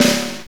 Index of /90_sSampleCDs/Northstar - Drumscapes Roland/KIT_Motown Kits/KIT_Motown Kit2x